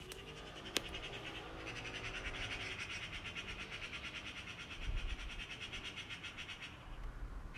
Omg ik zie ze nu met z’n tweeën een kat pestten en hij loopt nog weg ook??